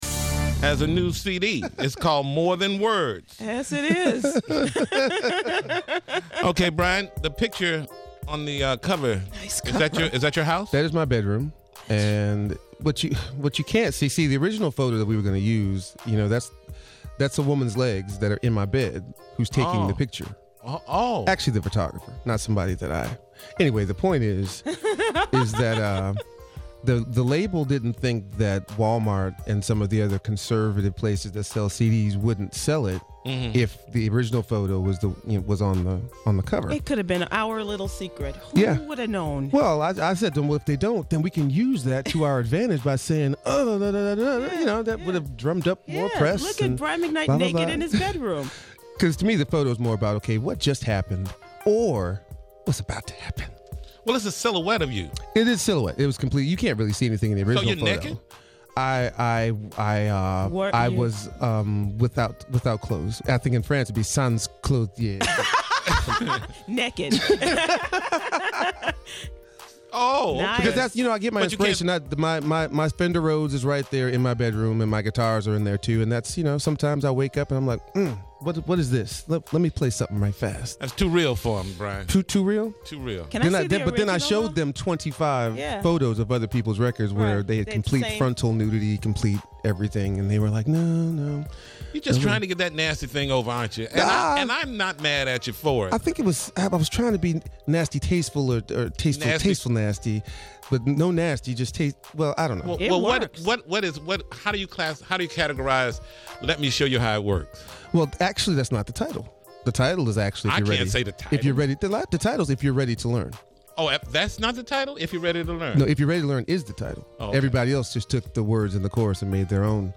Tom Joyner Morning Show Interviews Brian McKnight [AUDIO]
Brian McKnight talks to the Tom Joyner Morning Show about new album More Than Words and performs the In Studio Jam.